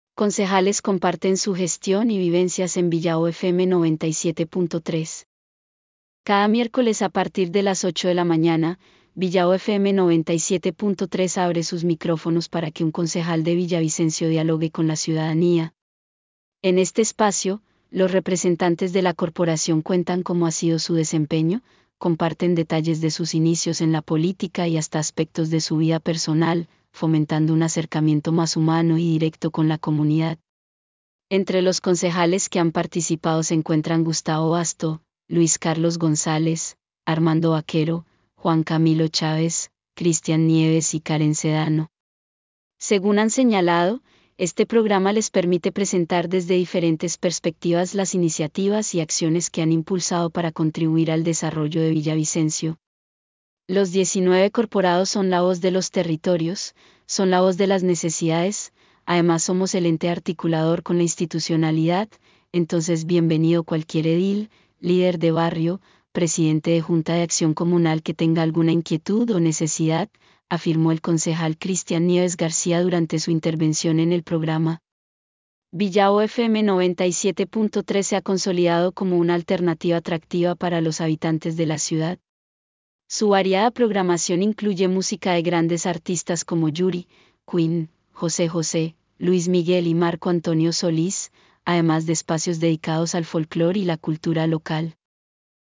Cada miércoles a partir de las 8:00 de la mañana, Villavo FM 97.3 abre sus micrófonos para que un concejal de Villavicencio dialogue con la ciudadanía. En este espacio, los representantes de la Corporación cuentan cómo ha sido su desempeño, comparten detalles de sus inicios en la política y hasta aspectos de su vida personal, fomentando un acercamiento más humano y directo con la comunidad.